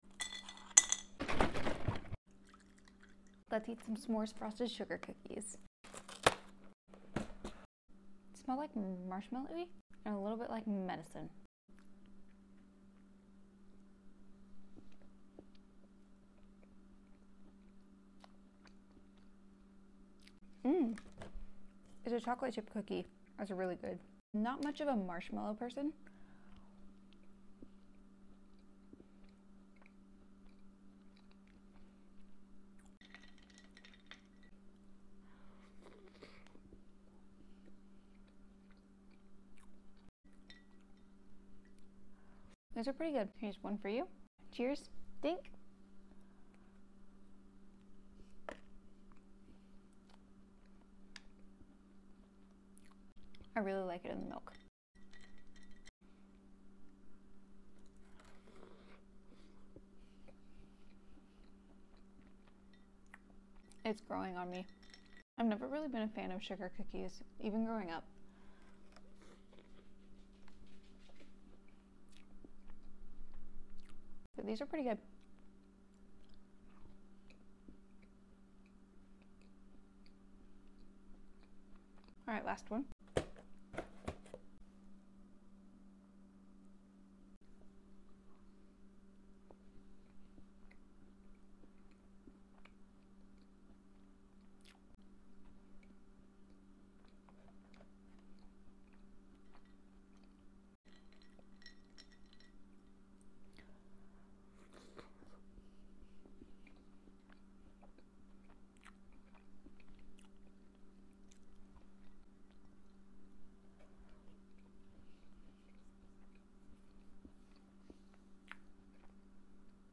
Smores Frosted Sugar Cookie Mukbang